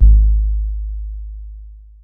Index of /90_sSampleCDs/Club_Techno/Percussion/Kick
Kick_03.wav